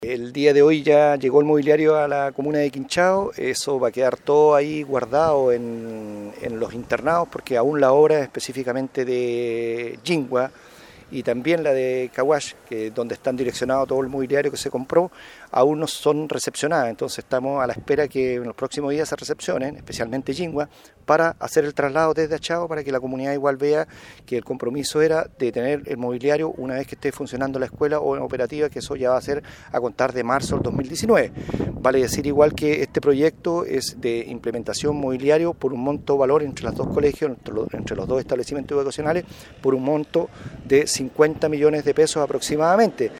El alcalde Washington Ulloa, señaló que la inversión fue cercana a los 50 millones de pesos.